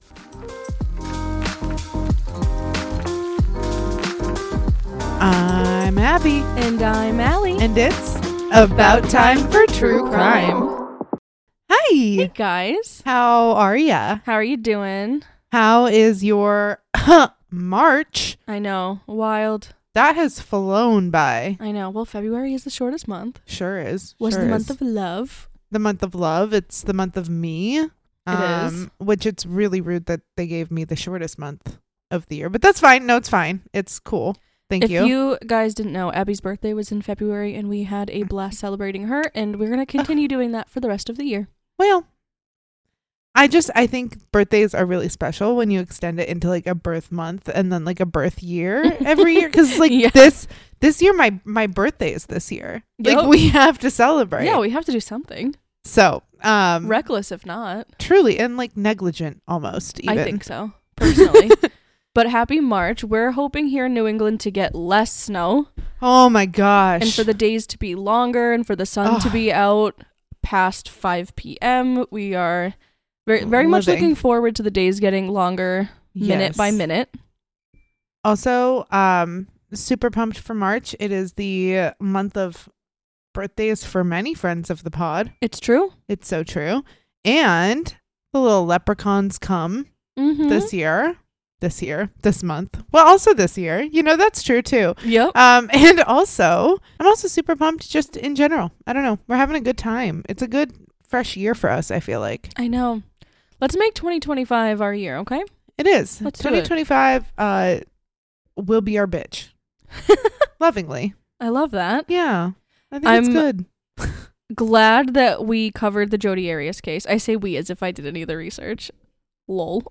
It’s about time you heard a true crime podcast from two educated women in the field.
These two young, excited and educated women set out to share with you true crime stories, from old and current, small town and national headlines, while also sharing personal experience as it relates.